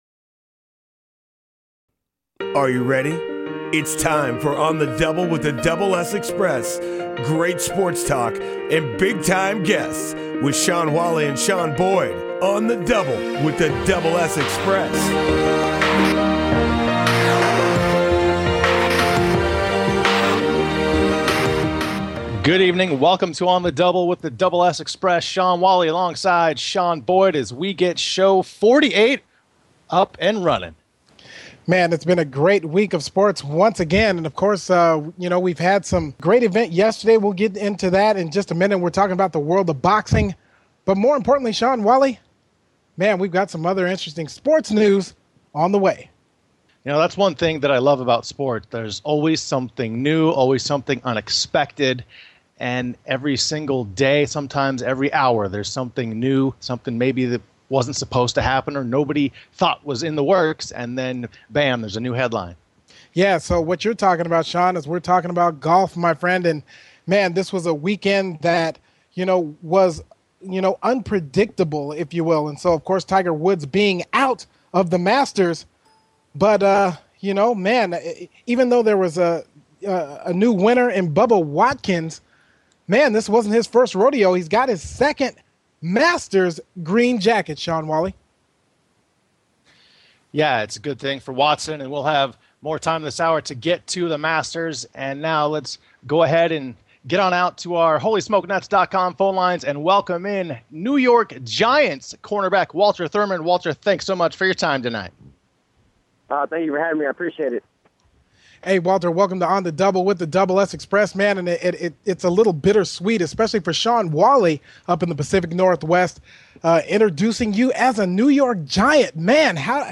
Talk Show Episode, Audio Podcast
New York Giants Cornerback Walter Thurmond is Big Time Guest #1! Thurmond talks about his time in Seattle with the Seahawks, and making the transition to the New York Giants!
On the Double with The Double S Xpress sports talk show; Sports Broadcasting; Play-by-Play; Analysis; Commentary; Insight; Interviews; Public Address Mission: To provide the best play-by-play & analysis of all sports in the broadcasting world.